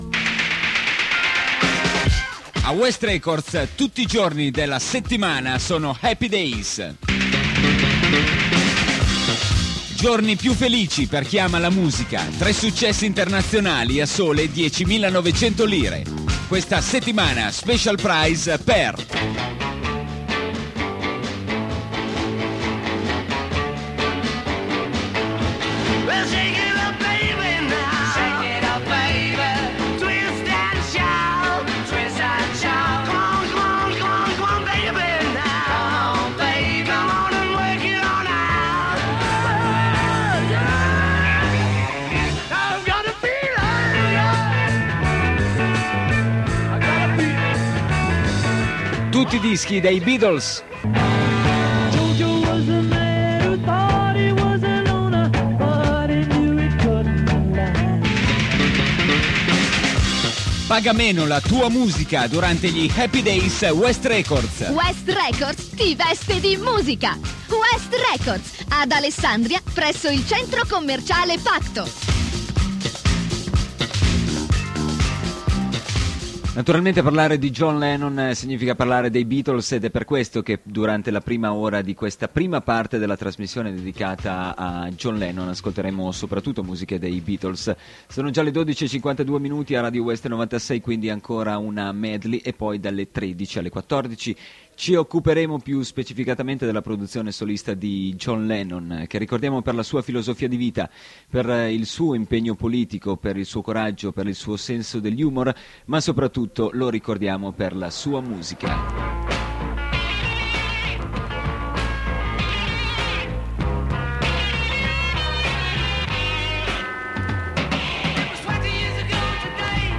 spot West Records, sponsor della trasmissione